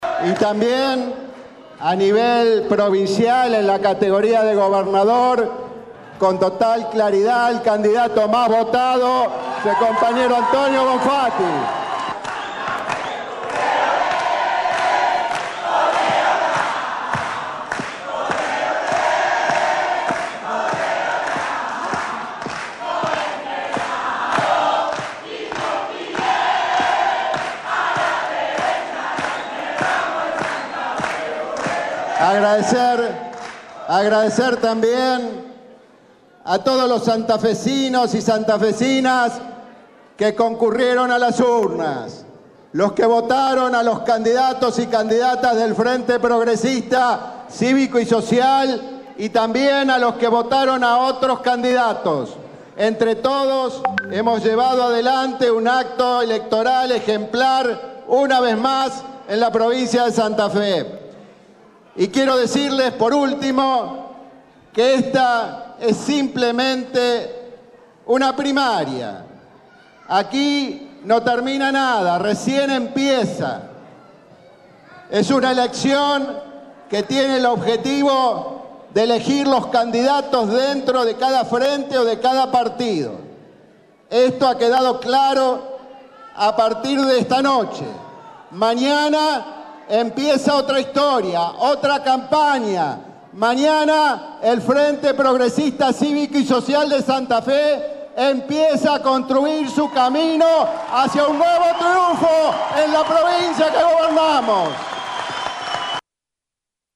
El gobernador de la Provincia, Miguel Lifschitz, candiato a diputado del Frente Progresista cerró la noche de este domingo con un acto donde ovacionaron a Emilio Jatón y a Antonio Bonfatti como  los candidatos más votados de la provincia y la ciudad de Santa Fe.